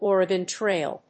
アクセントÓregon Tráil